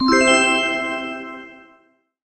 gameComplete.ogg